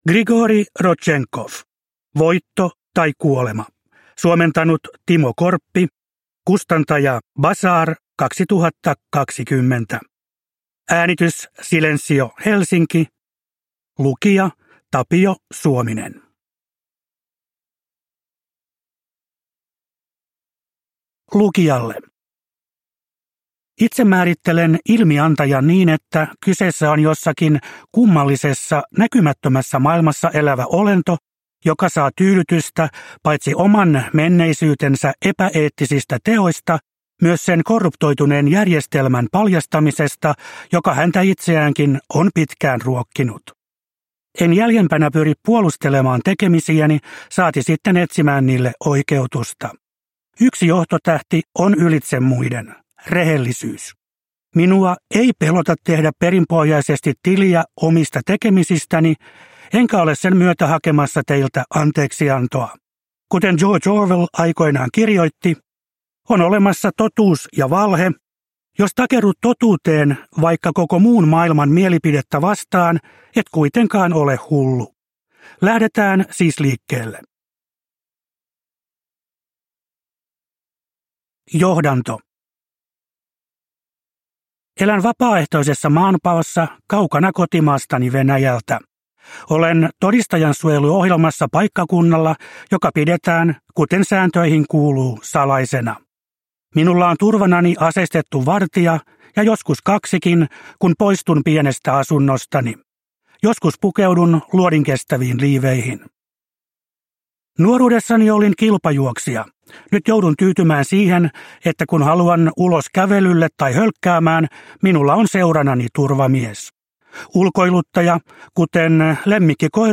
Voitto tai kuolema – Ljudbok